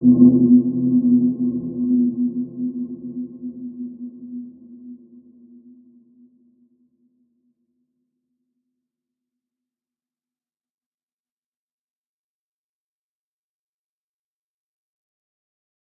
Dark-Soft-Impact-B3-p.wav